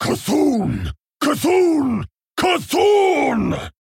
VO_OG_162_Male_Panderan_Play_01.mp3